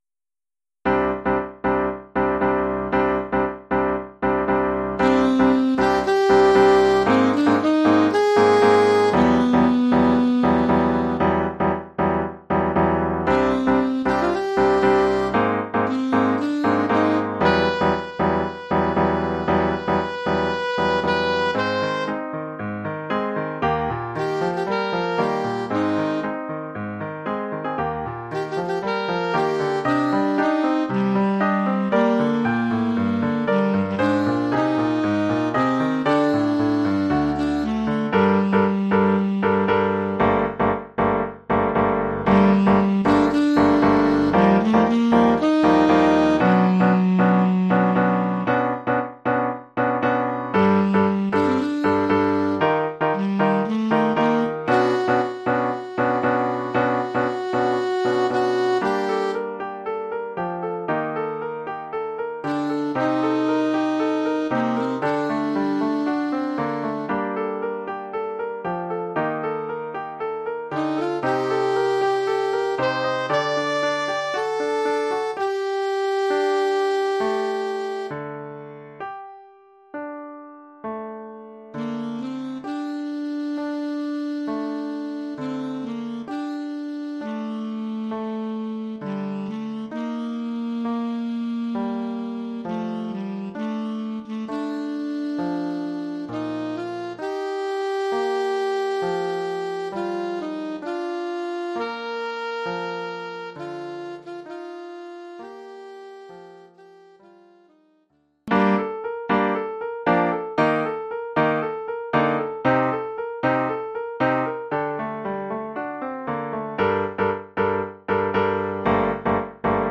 Formule instrumentale : Saxophone alto et piano
Oeuvre pour saxophone alto et piano.